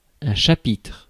Ääntäminen
Synonyymit paragraphe rubrique Ääntäminen France: IPA: [ʃa.pitʁ] Haettu sana löytyi näillä lähdekielillä: ranska Käännös Ääninäyte Substantiivit 1. capítulo {m} Suku: m .